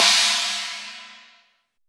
LITE CHINA.wav